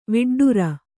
♪ viḍḍura